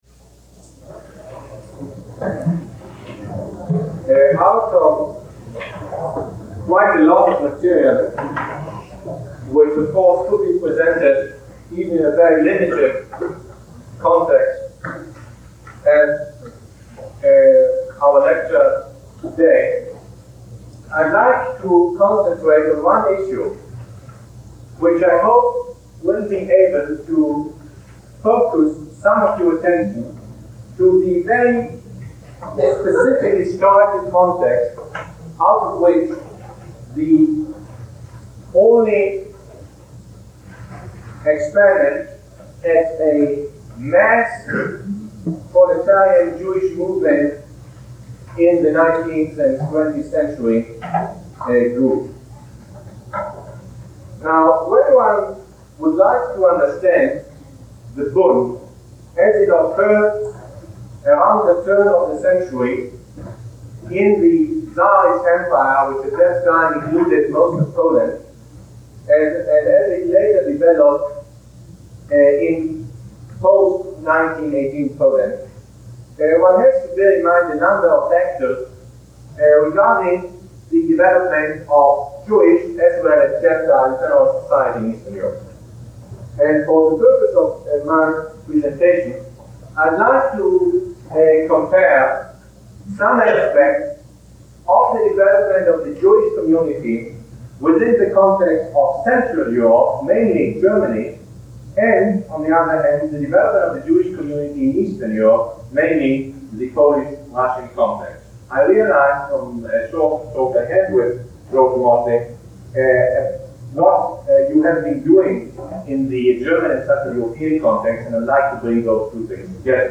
Lecture #23 - April 23, 1971